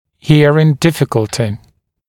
[‘hɪərɪŋ ‘dɪfɪkəltɪ][‘хиэрин ‘дификэлти]проблема со слухом